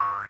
boing_3.ogg